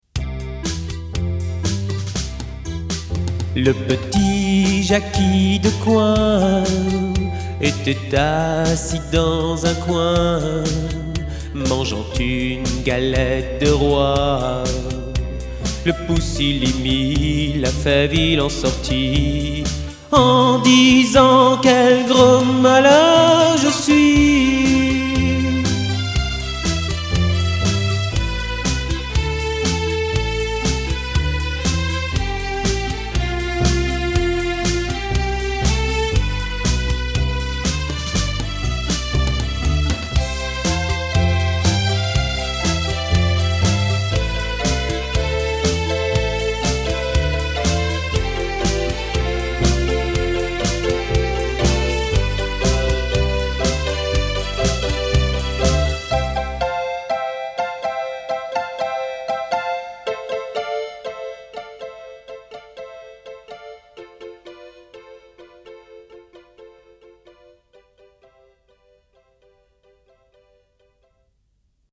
Comptine de ma mère l’oie « Jacky Decoin »